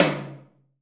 TOM 1H.wav